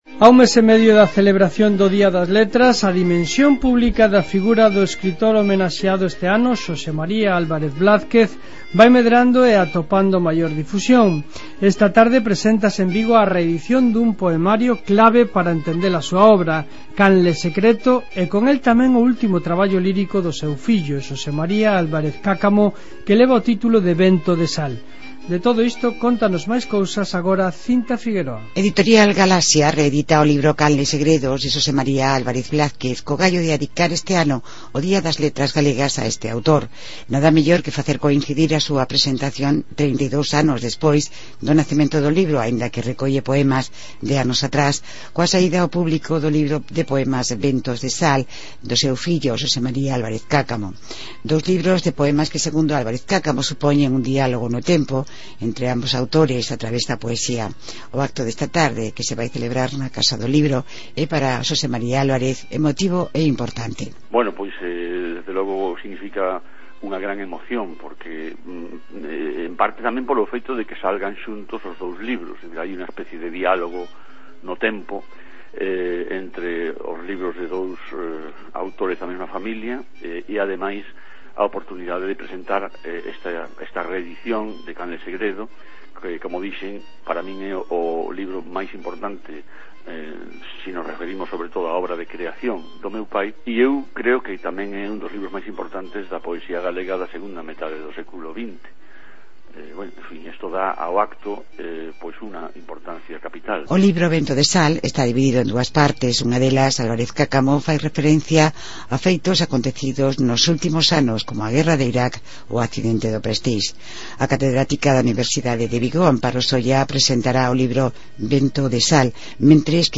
em Radio Nacional de España na Galiza